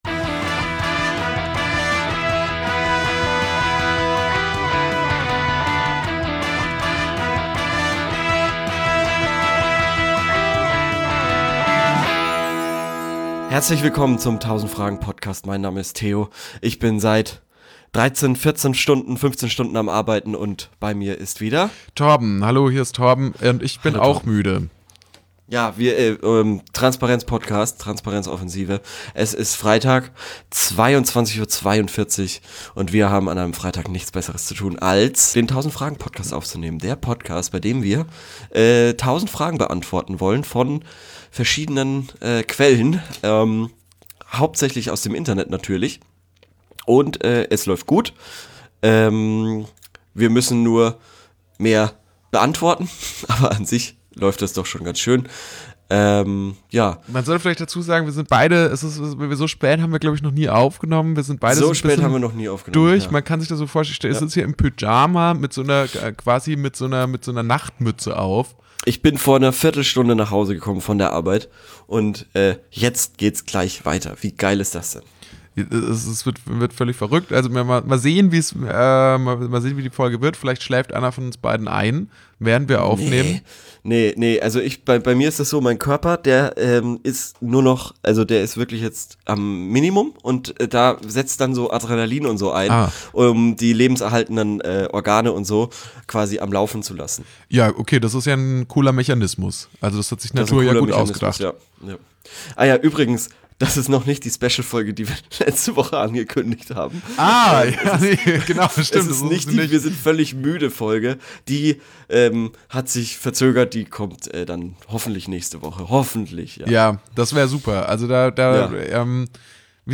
Late-Night-Atmosphäre kommt auf.